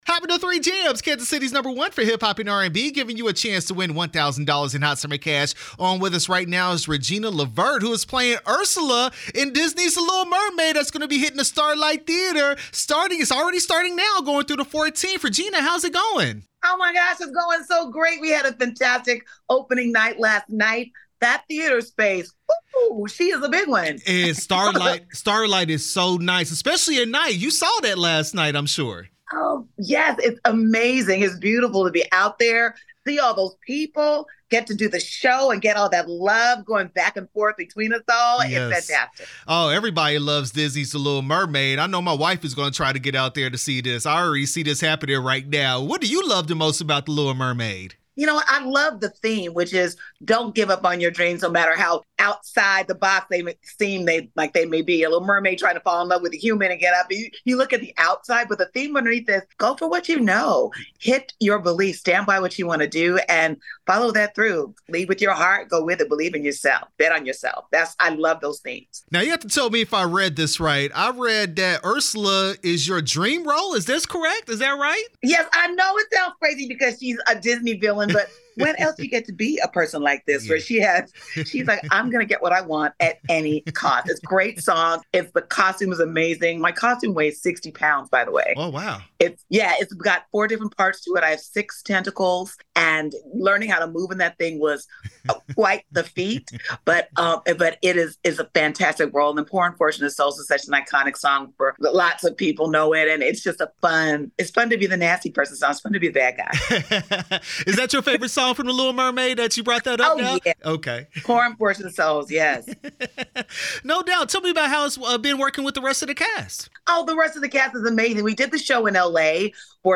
Starlight Theater-Disney’s The Little Mermaid interview 7/10/24